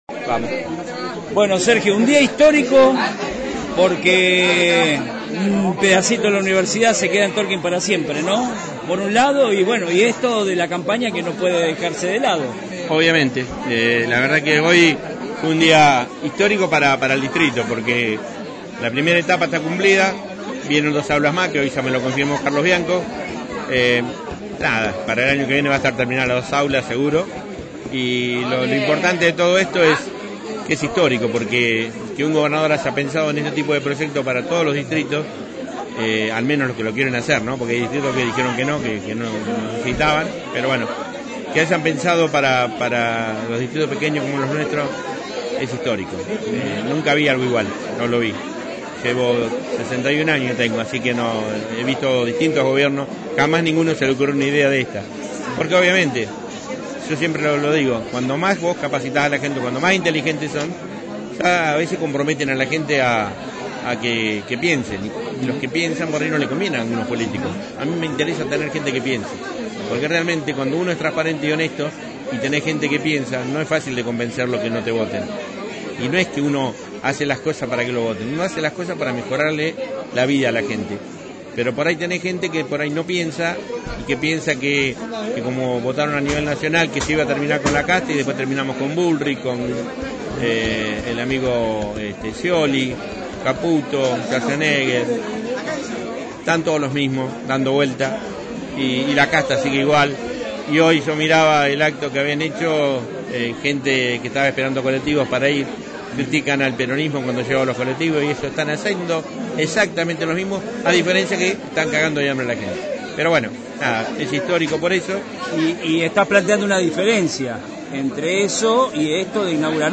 En el acto de presentación de la lista de Fuerza Patria en Saldungaray, el intendente destacó la inauguración de Centro Universitario como un proyecto histórico en el distrito y anunció la erradicación del basurero a cielo abierto, entre otras importantes obras.
Sergio-Bordoni-Fuerza-patria-Saldungaray.mp3